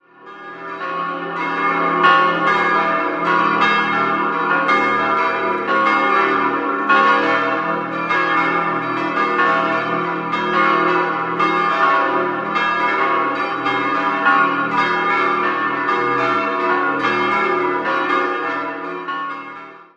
5-stimmiges Geläute: cis'-e'-gis'-h'-d'' Die große Glocke wurde 1950 von der Firma Gebhard in Kempten gegossen, die vier kleineren im Südturm bilden einen komplett historischen Bestand.